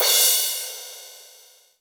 ROCK CRASH.wav